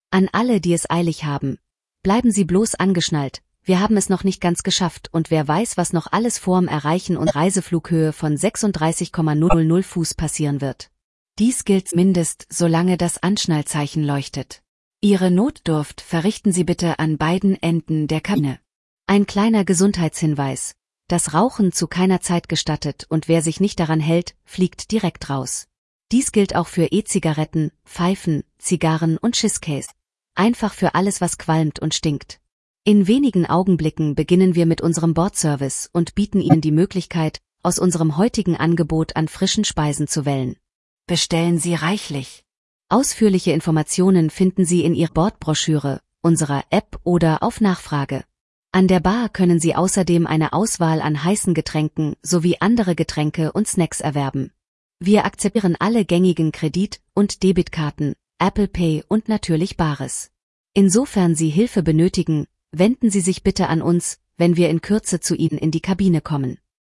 AfterTakeoff.ogg